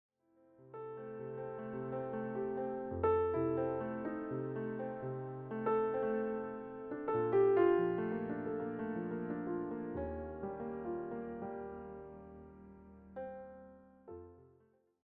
a consistent, relaxed romantic mood